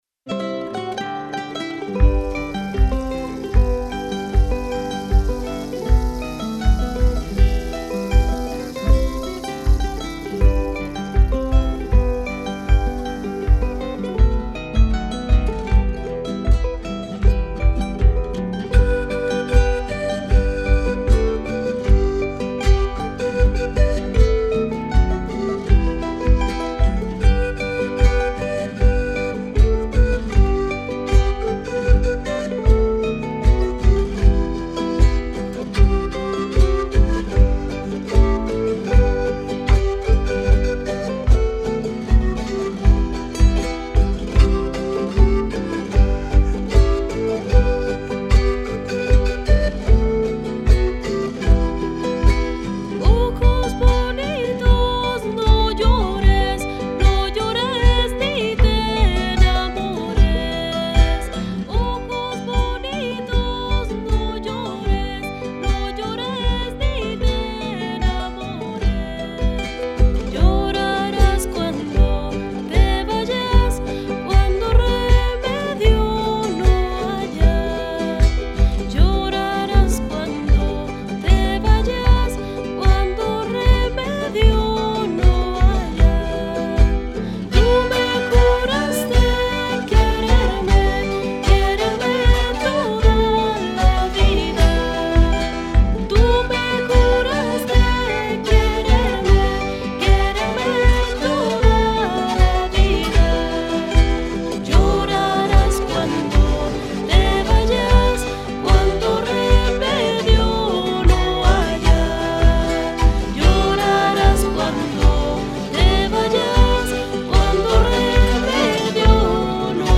Huayno tradicional peruano